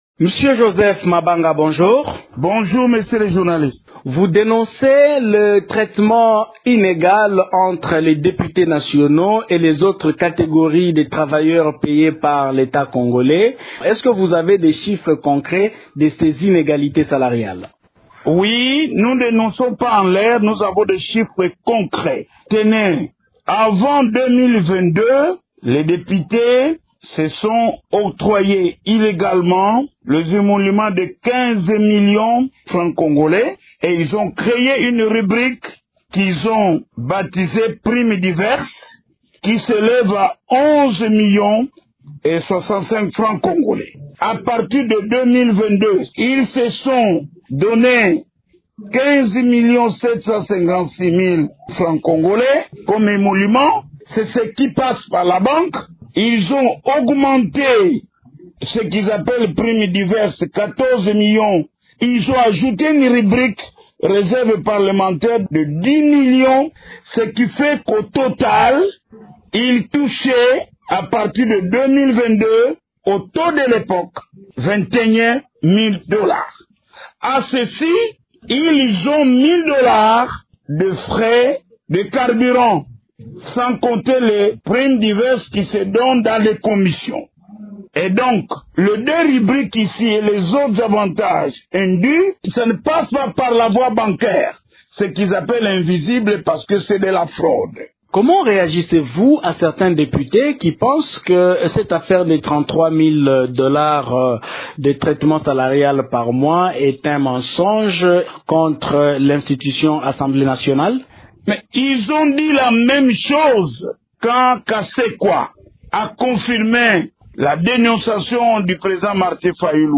s'entretient à ce sujet avec